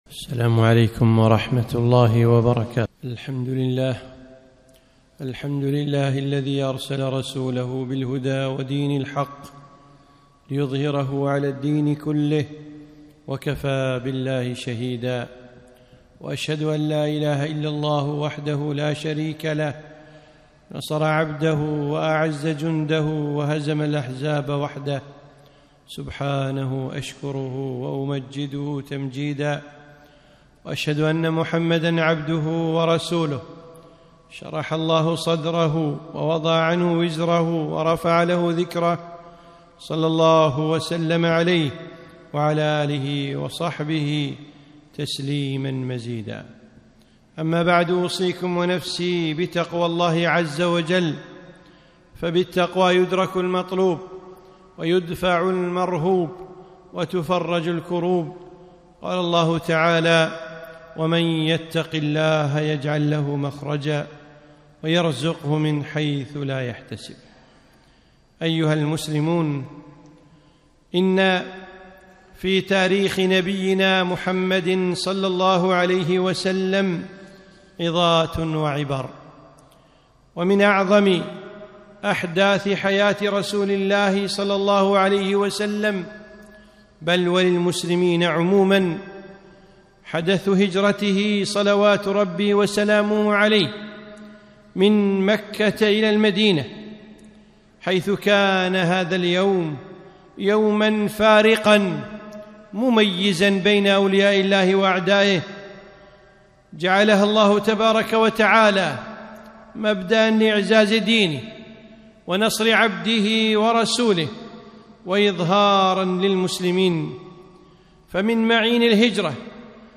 خطبة - الهجرة عظات وعبر